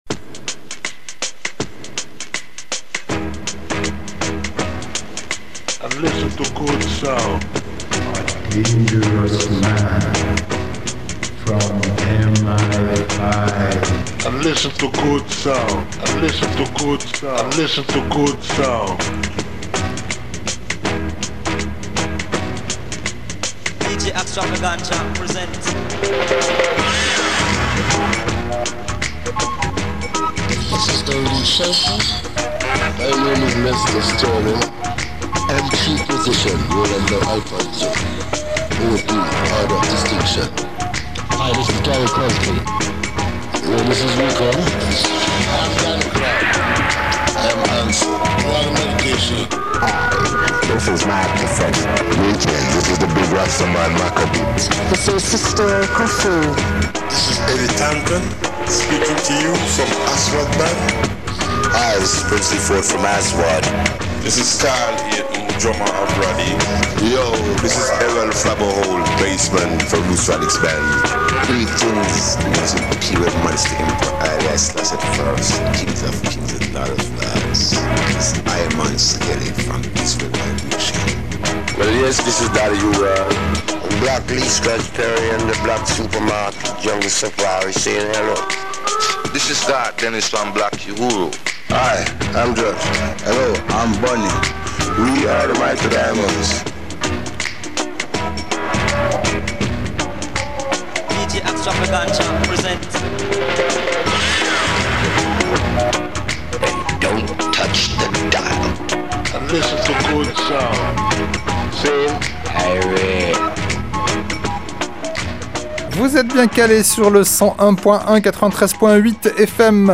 Black Super Market – radio show !
dub, salsa, funk, mestizo, ska, afrobeat, reggaeton, kompa, rumba, reggae, soul, cumbia, ragga, soca, merengue, Brésil, champeta, Balkans, latino rock…